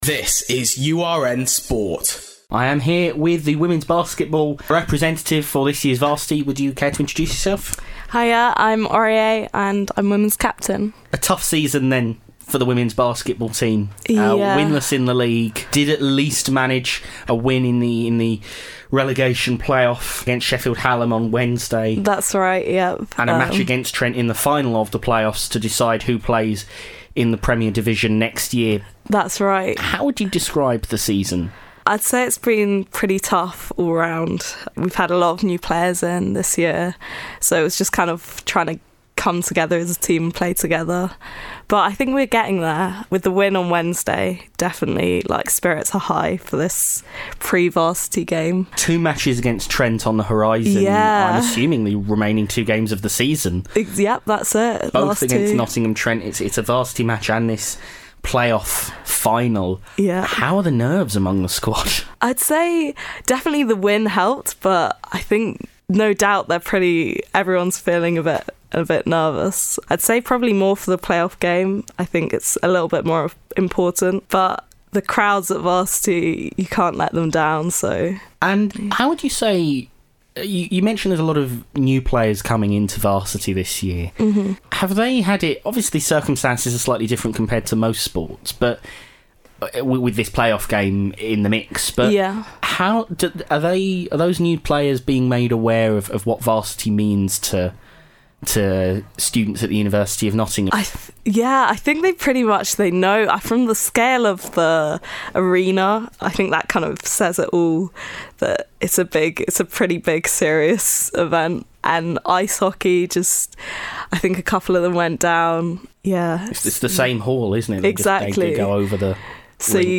Varsity 2018 Basketball pre-match interviews
Ahead of today's major clashes at the Motorpoint Arena, we sat down with both the men's and women's teams to hear their thoughts ahead of these critical games that could win UoN the series.